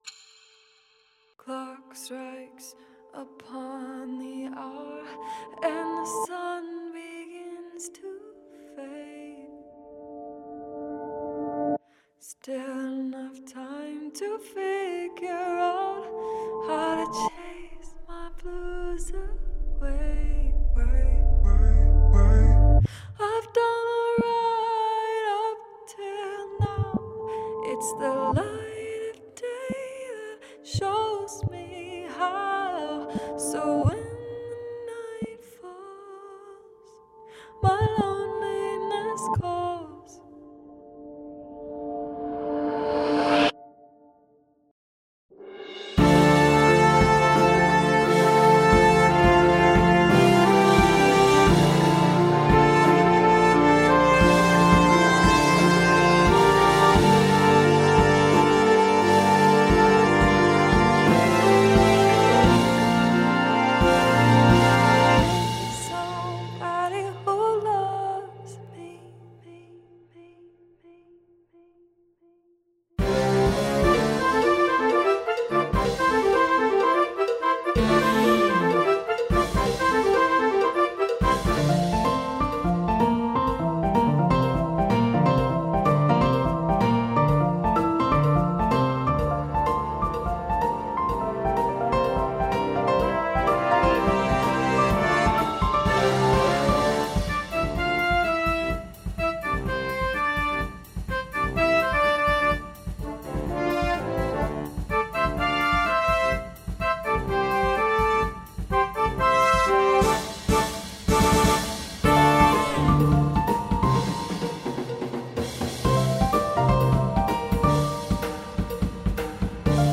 Features solo space for sax or any instrument.